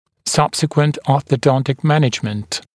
[‘sʌbsɪkwənt ˌɔːθə’dɔntɪk ‘mænɪʤmənt][‘сабсикуэнт ˌо:сэ’донтик ‘мэниджмэнт]последующее ортодонтическое лечение